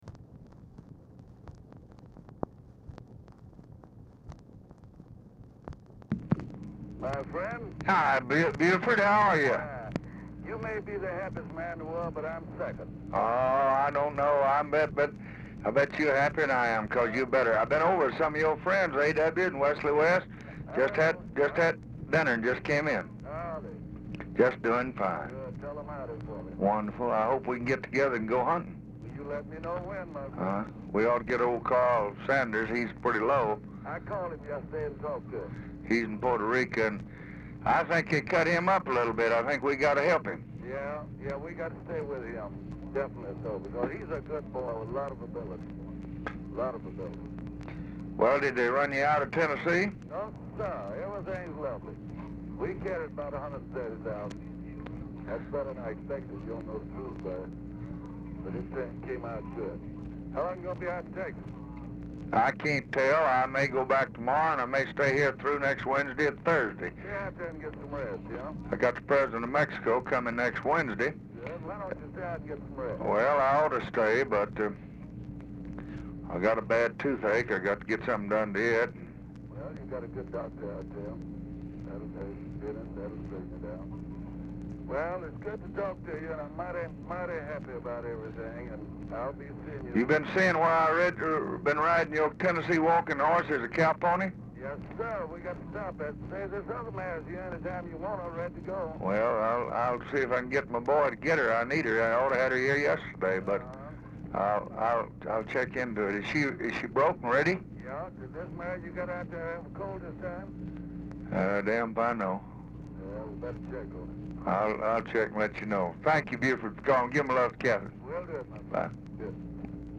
Telephone conversation # 6245, sound recording, LBJ and BUFORD ELLINGTON, 11/5/1964, 9:55PM | Discover LBJ
Format Dictation belt
Location Of Speaker 1 LBJ Ranch, near Stonewall, Texas